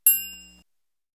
MD (Triangle 1).wav